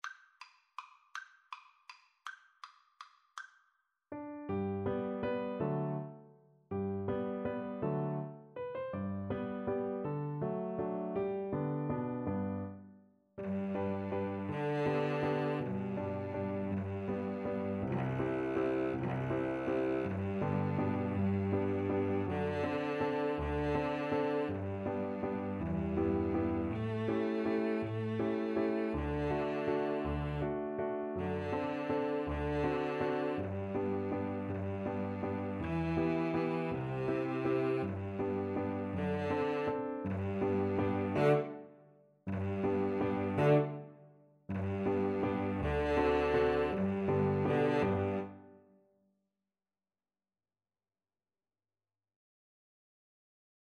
3/4 (View more 3/4 Music)
One in a bar . = c.54
Pop (View more Pop Piano Trio Music)